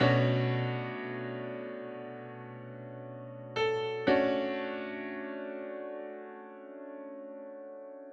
12 Piano PT1.wav